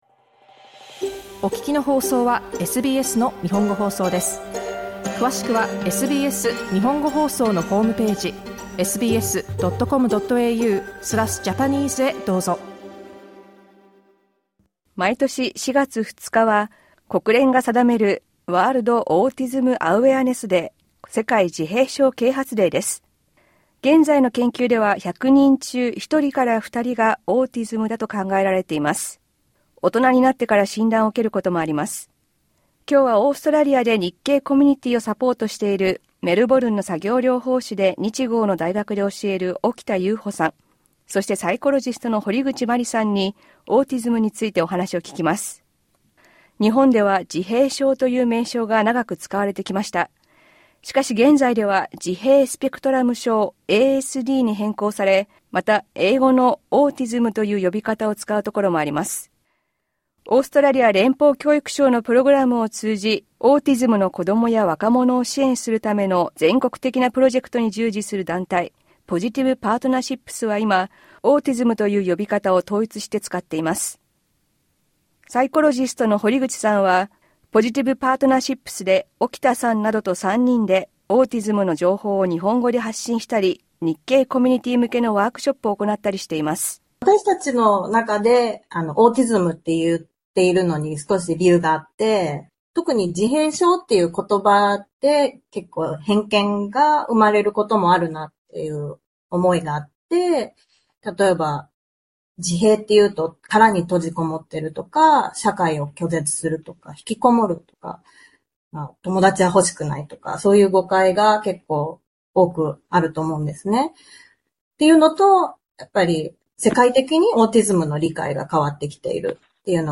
Insights from two Japanese experts